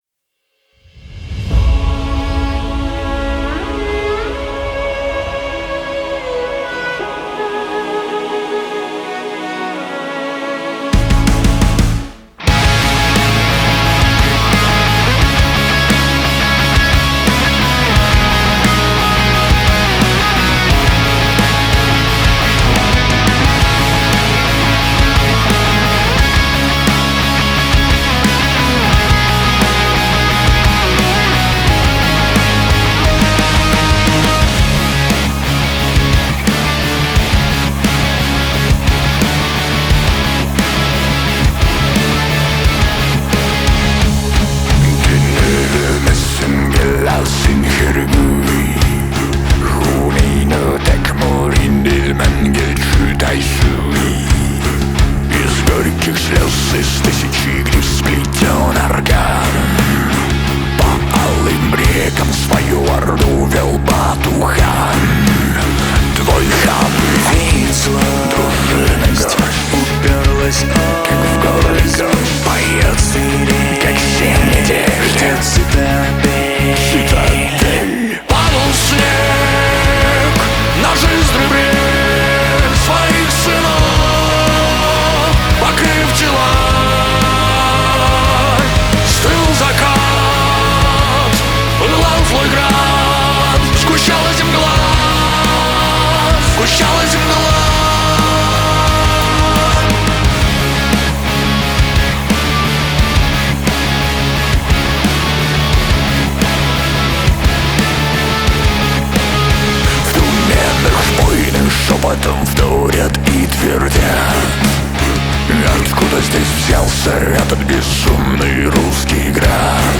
Рок музыка